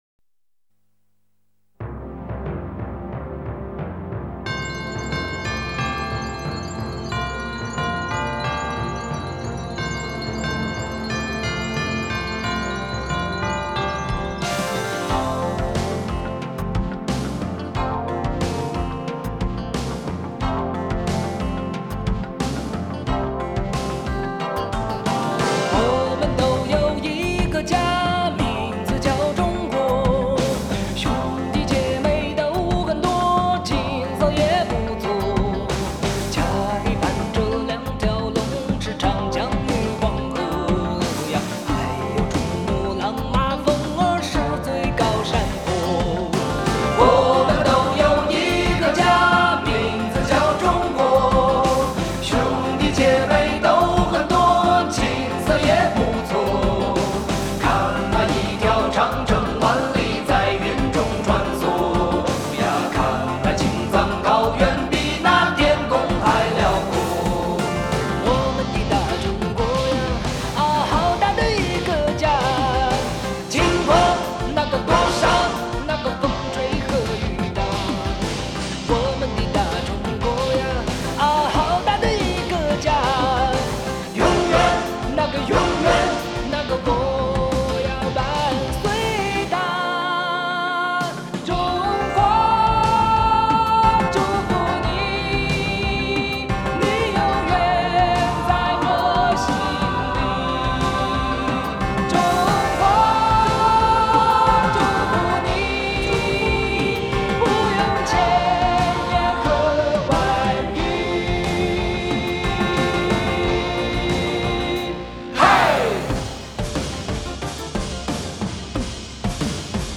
华语怀旧